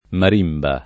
[ mar & mba ]